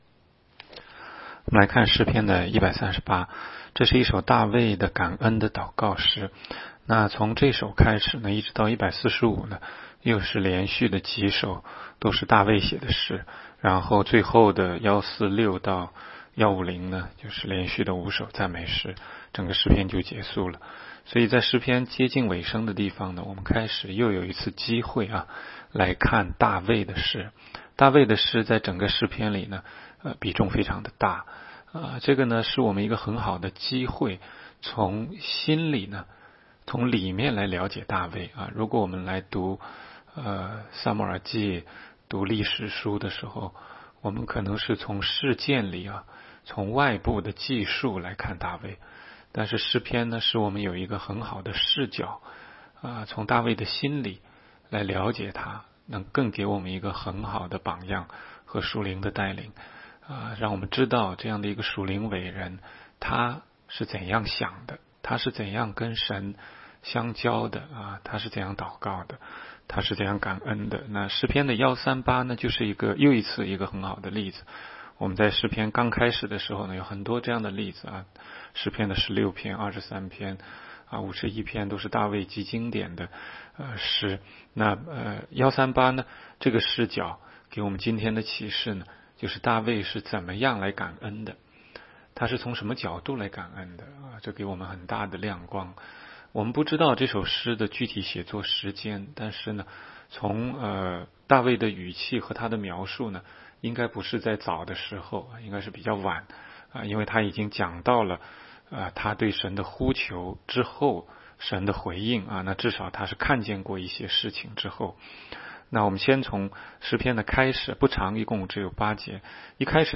16街讲道录音 - 每日读经